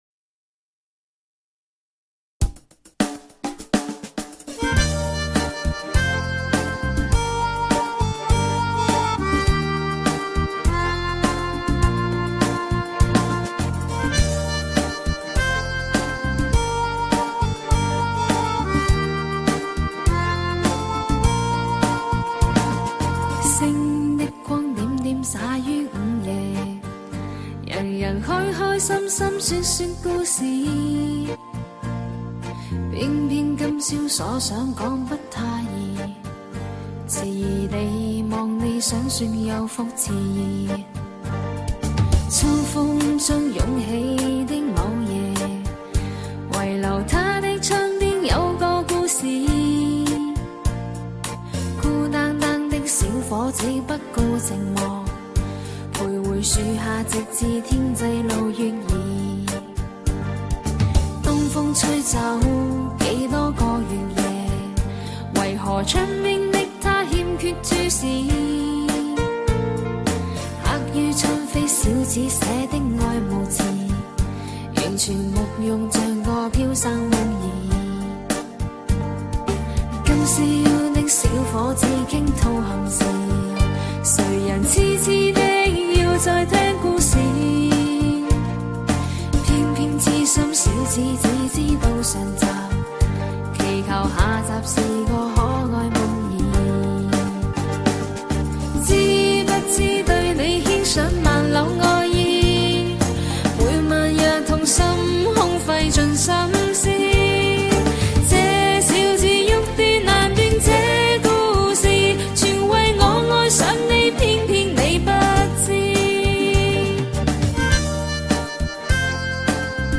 歌曲以舒缓轻柔的旋律开场，如同一股潺潺流淌的清泉，为听众营造出温馨而略带忧伤的氛围，仿佛在耐心等待着那个故事的展开。